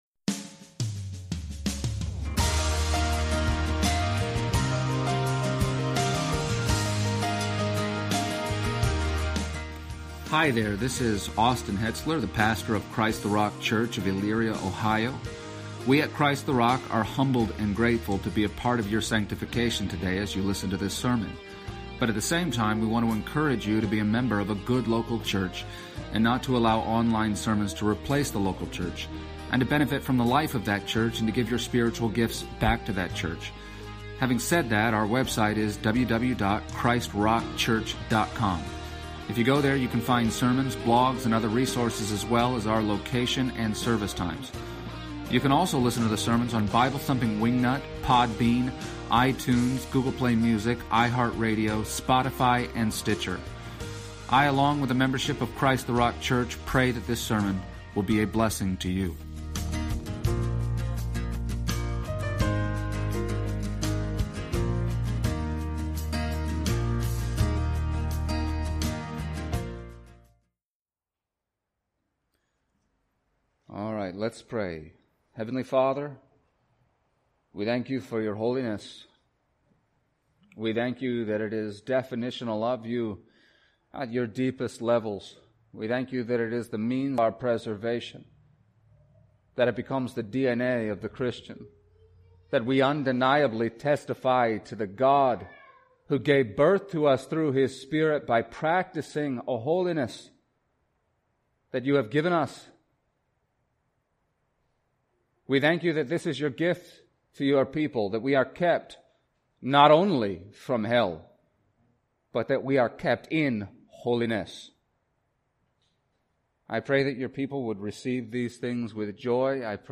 Passage: John 17:11-19 Service Type: Sunday Morning